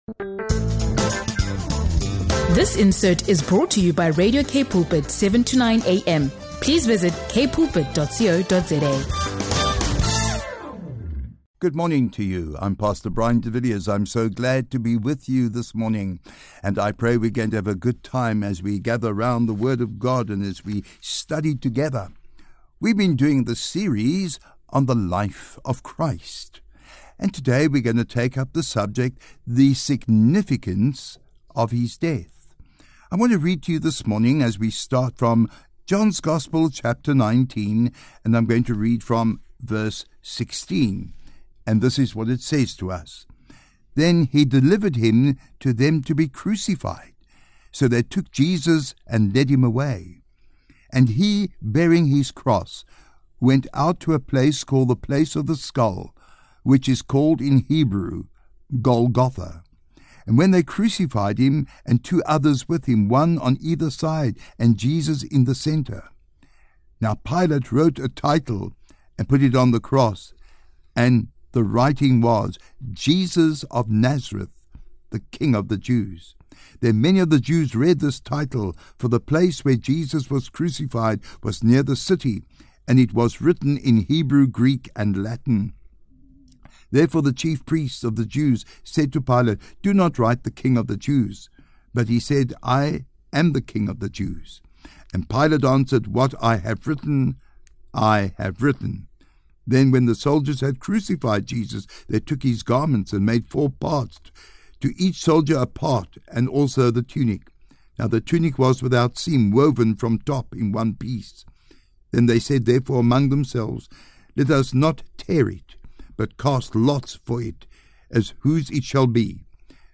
This episode highlights the uniqueness and eternal impact of His death, showing how it brings forgiveness for the past, strength for the present, and hope for the future. A deep yet accessible teaching on the life-changing power of Christ’s sacrificial love.